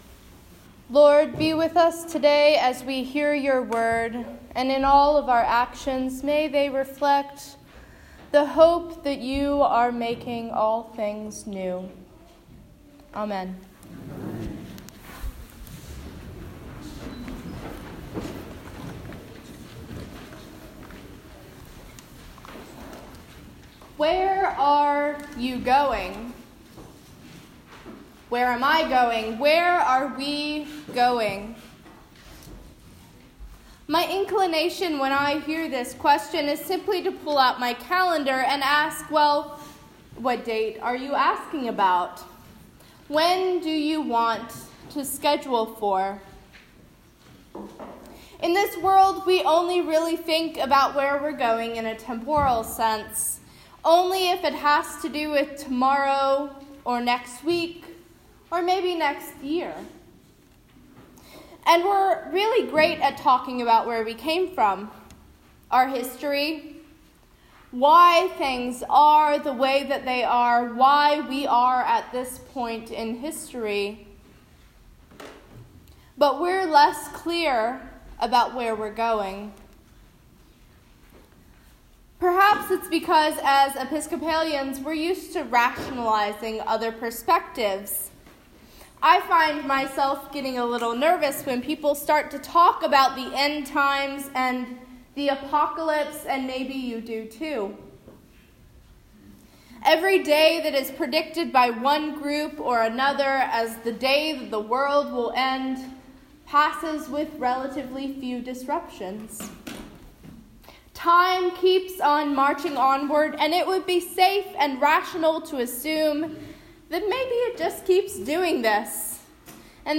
All Things New (Sermon)
sermon11_4_18.m4a